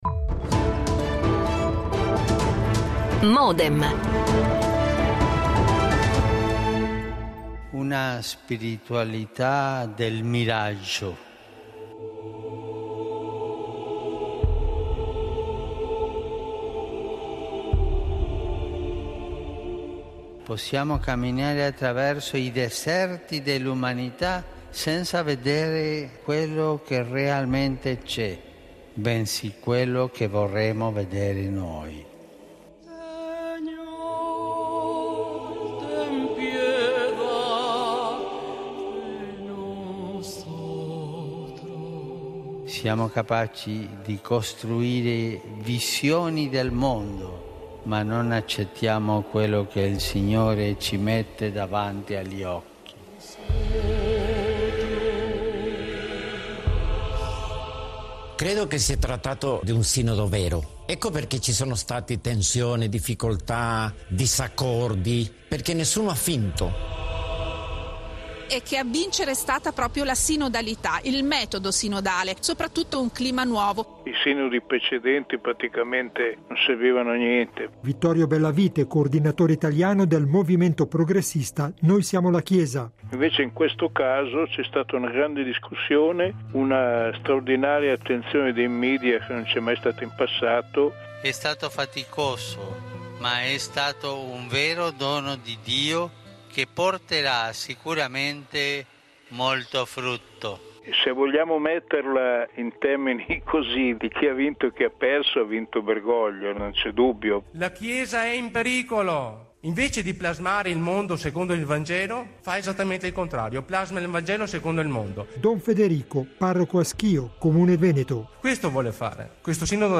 Oltre ad un’intervista registrata con il vescovo di Lugano, Mons. Valerio Lazzeri intervengono: